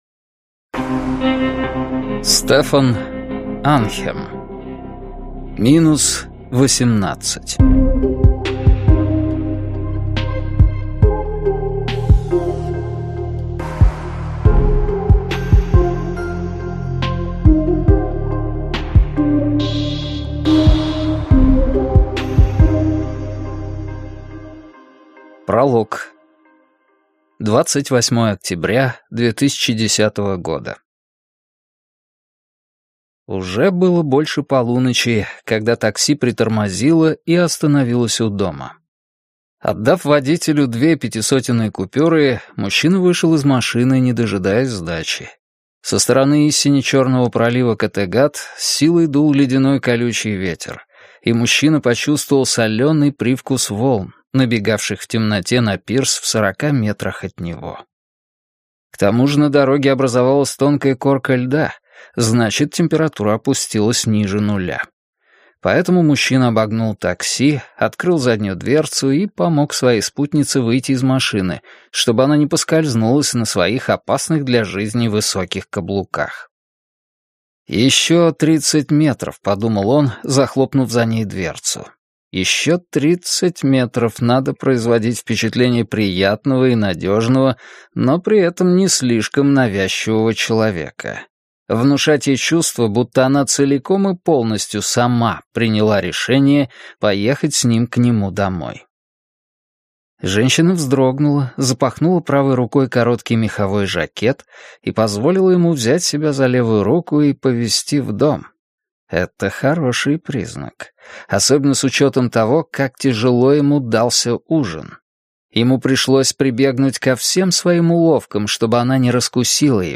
Аудиокнига Минус восемнадцать | Библиотека аудиокниг